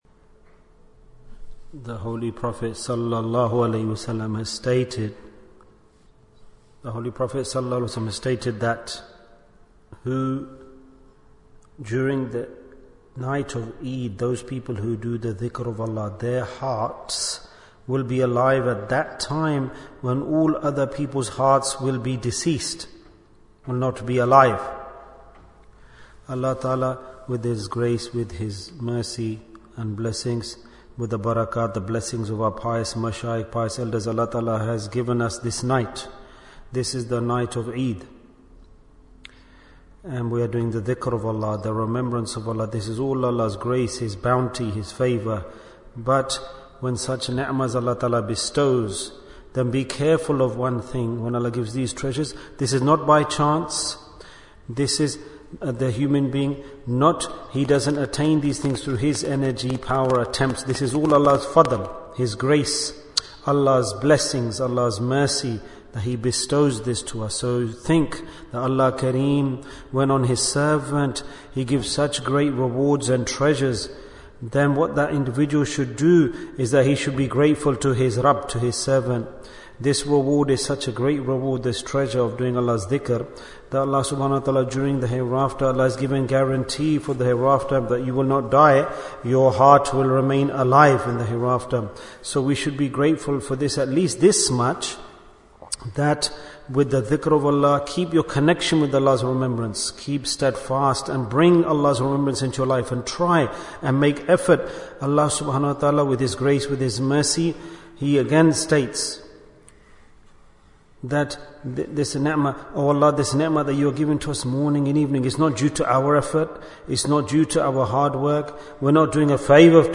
Bayan, 5 minutes